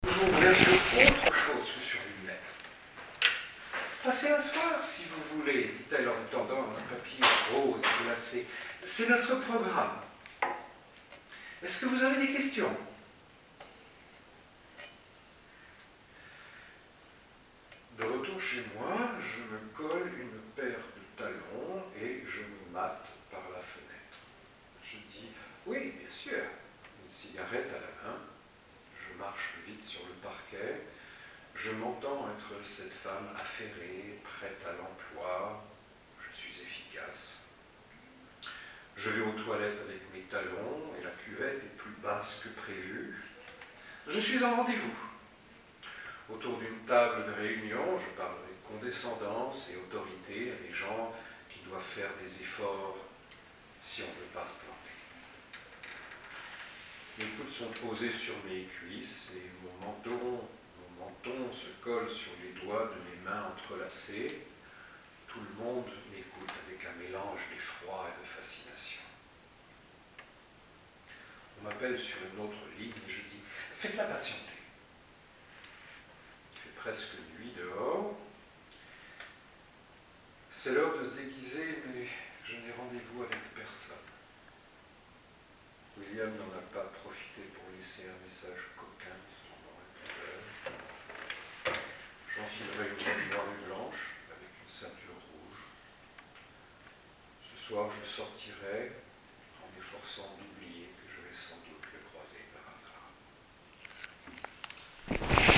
Lieu : Crac Alsace, Altkirch
Installés dans la grande bibliothèque qui jouxte le café, c’est avec une grande écoute et une application unanime pour lire le texte à haute voix, que les lecteurs ont bouclé le récit en un peu plus de deux heures.
La bibliothèque en vadrouille a saisi deux instants, le premier pendant la lecture et le second quelques minutes après la fin de la lecture collective, où des réactions spontanées ont émergées.